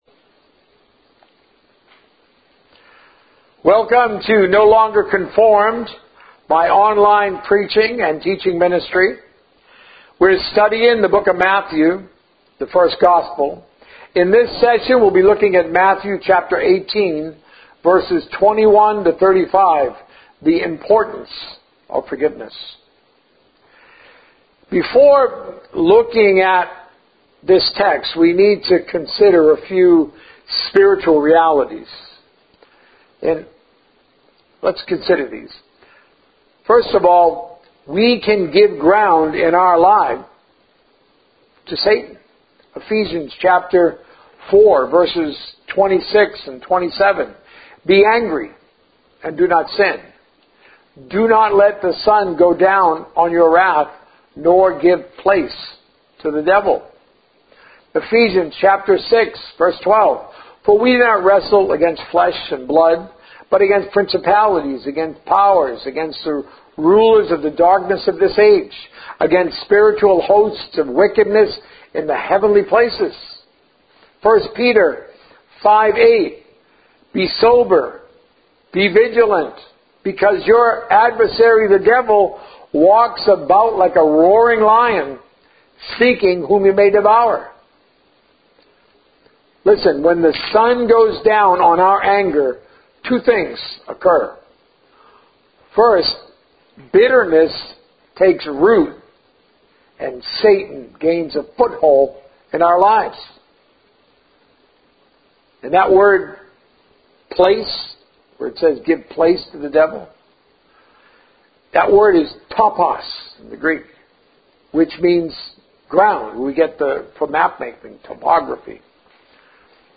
A message from the series "The First Gospel." A Ministry of the Ages Begins